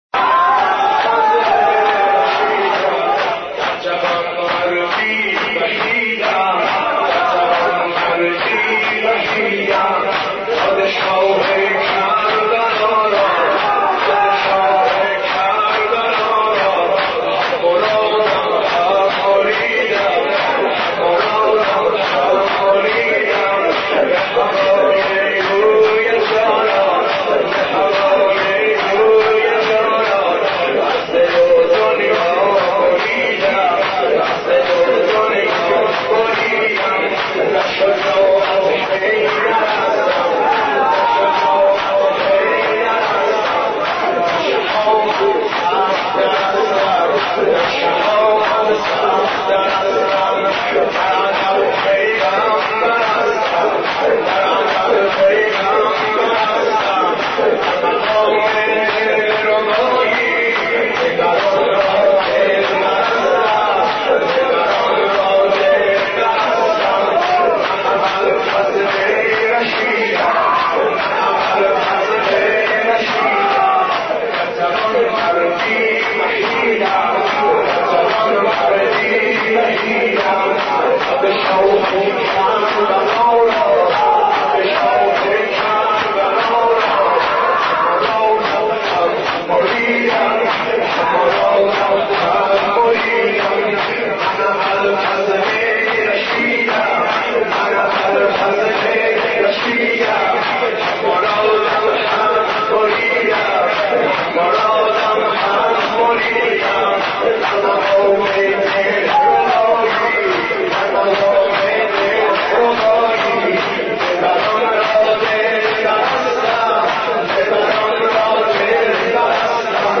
حضرت عباس ع ـ شور 1